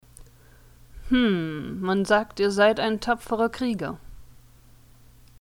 Deutsche Sprecher (f)